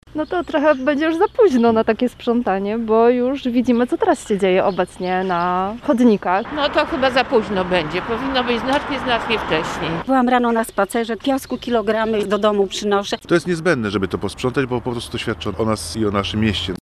sonda-Gdynia.mp3